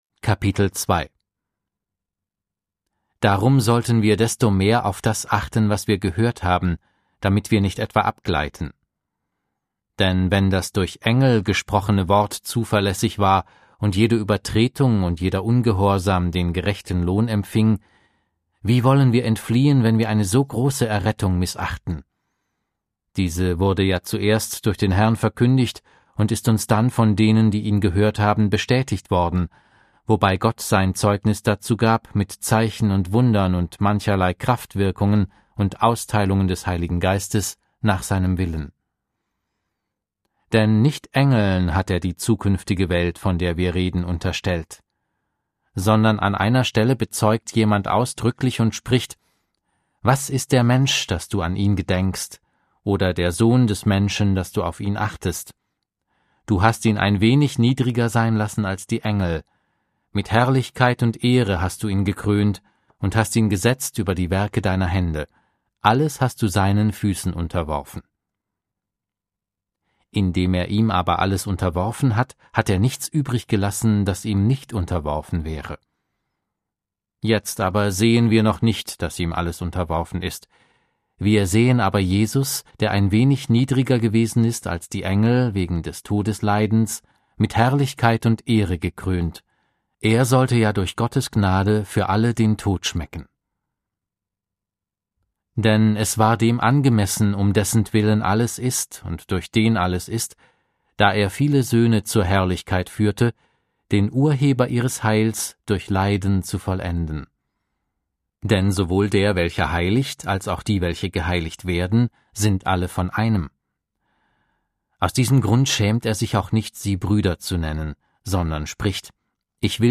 Audio Bibel - Schlachter 2000